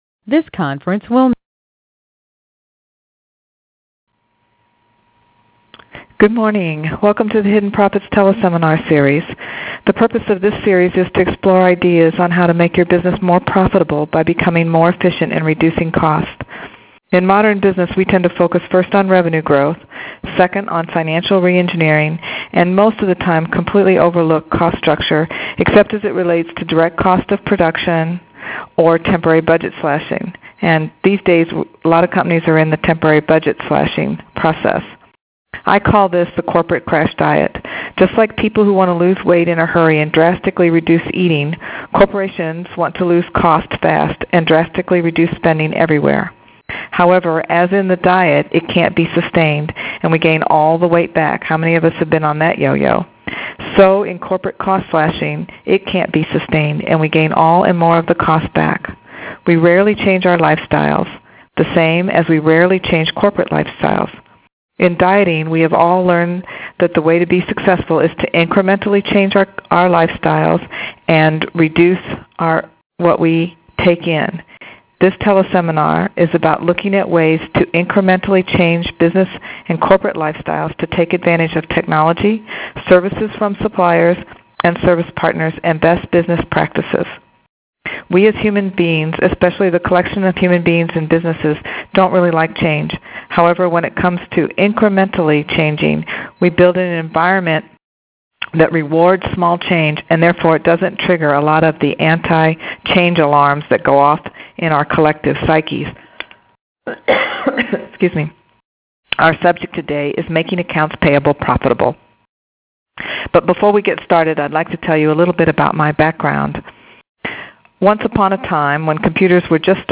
AP Process Teleseminar